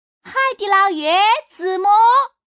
Index of /xiaoxiang/update/3018/res/sfx/changsha_woman/